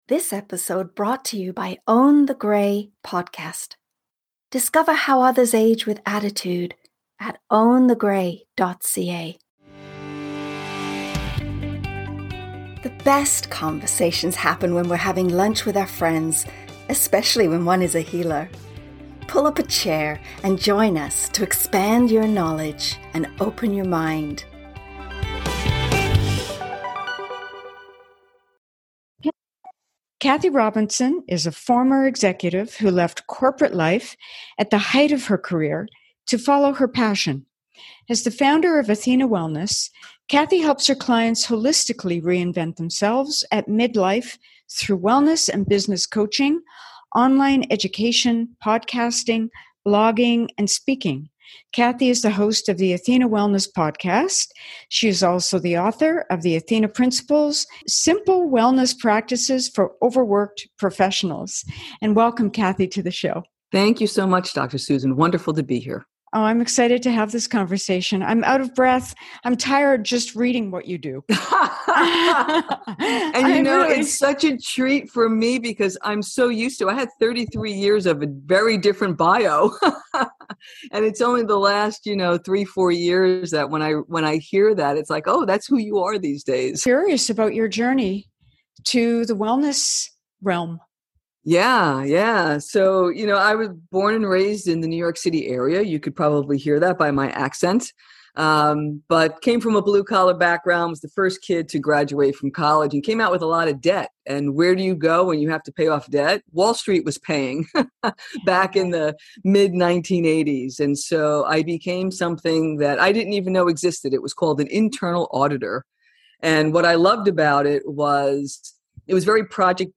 We had a terrific conversation that I know you will enjoy!!